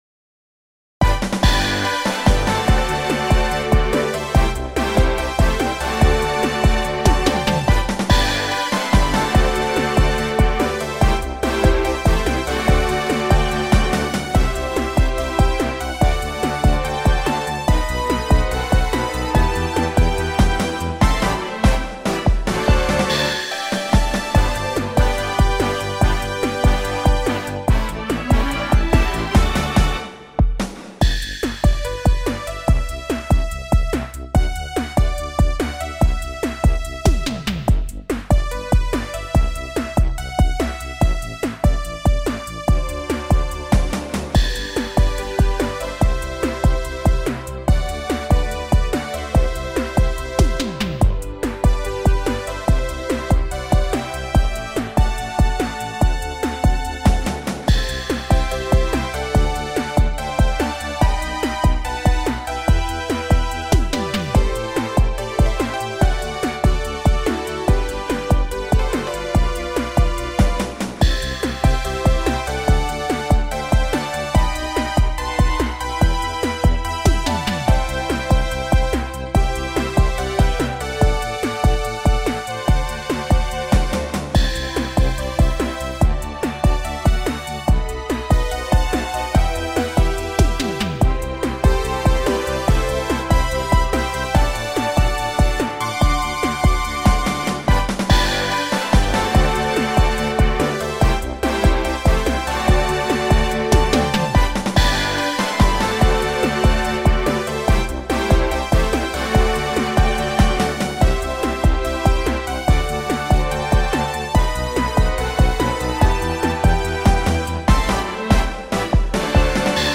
アップテンポインストゥルメンタルロング